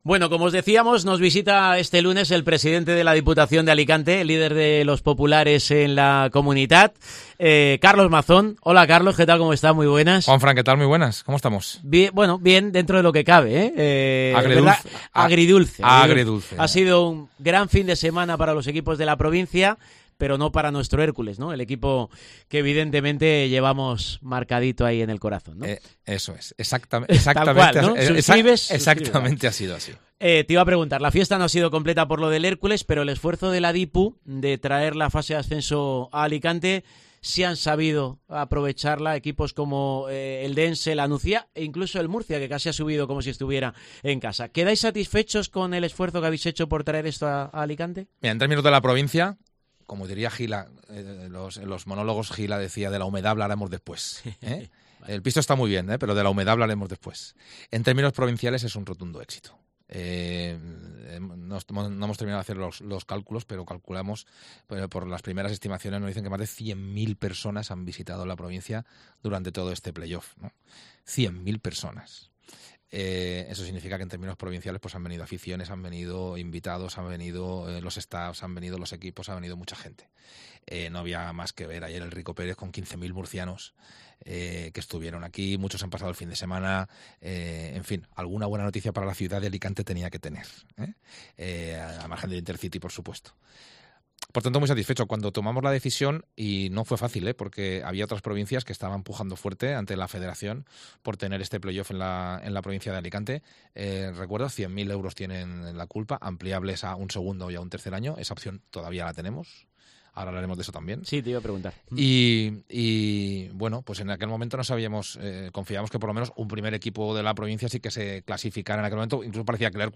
Entrevista a Mazón sobre la actualidad del Hércules